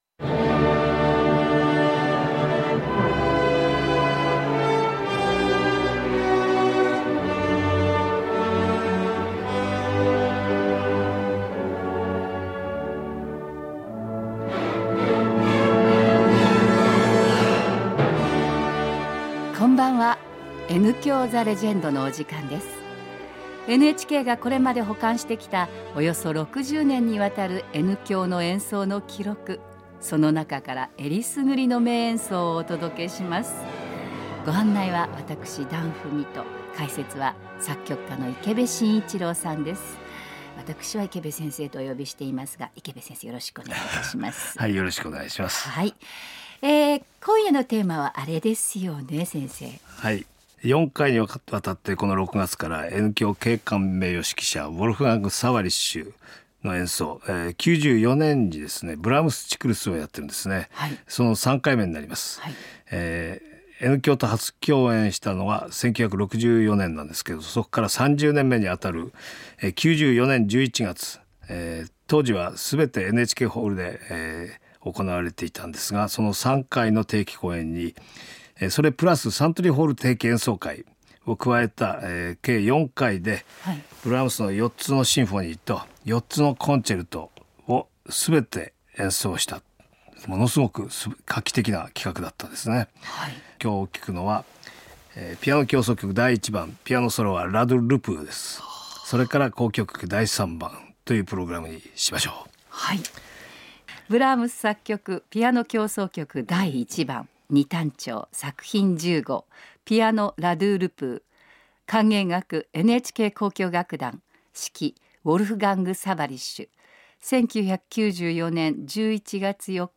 Radu Lupu, Piano – NHK Symphony, conducted by Wolfgang Sawallisch – November 4, 1994 – NHK-Radio, Tokyo –
From Tokyo, the legendary NHK Symphony, guest conducted by Wolfgang Sawallisch and featuring the great Romanian pianist Radu Lupu in an all-Brahms program, broadcast on November 4, 1994.
Not a very long concert, with only two works featured, but an excellent collaboration between soloist, conductor and Orchestra, and a rapturous audience, so quiet you can hear a pin drop, and who wait for a breath before offering thunderous applause.